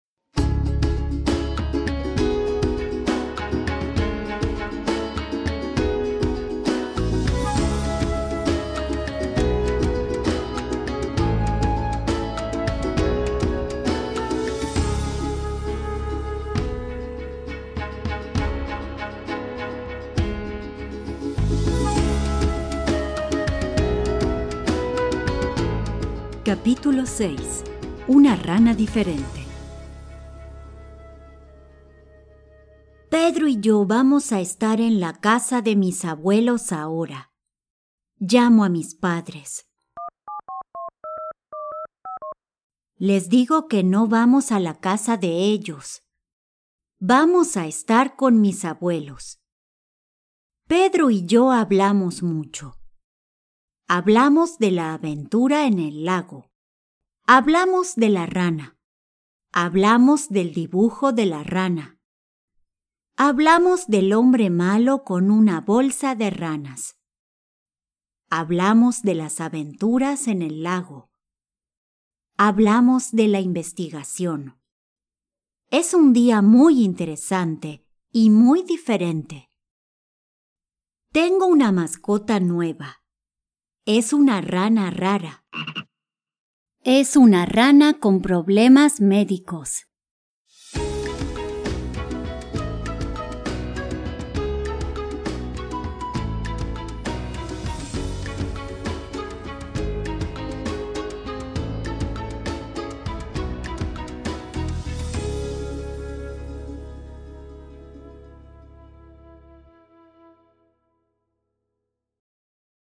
Each audio book contains original music, sound effects and voice acting from Native Spanish speakers to ensure a memorable experience for your students!
Los Niños Detectives Audio Book Sample